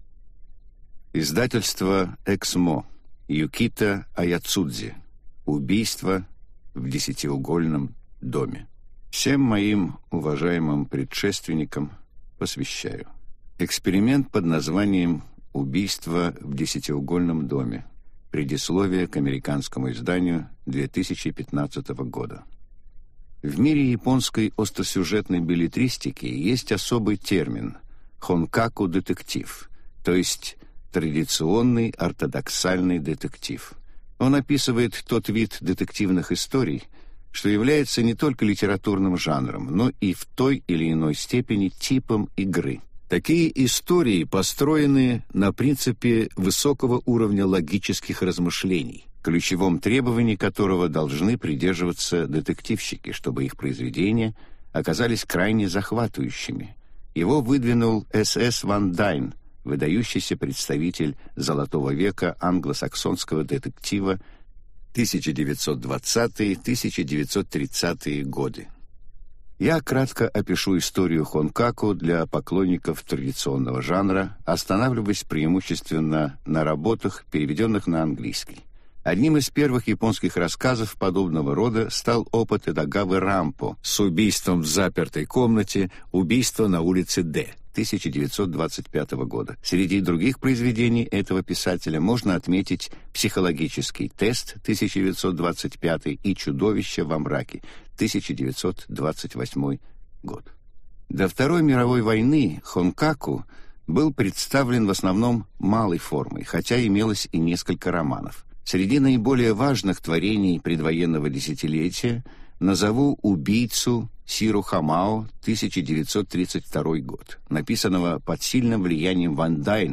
Аудиокнига Убийства в десятиугольном доме | Библиотека аудиокниг